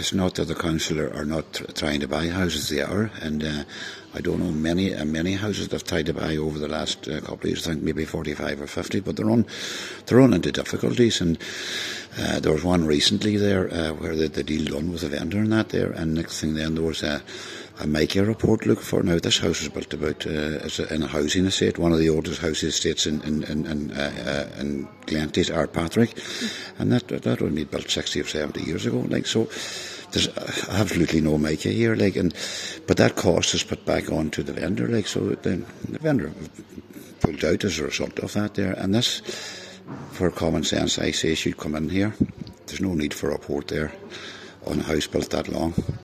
He says there needs to be a review of criteria governing the purchasing of houses: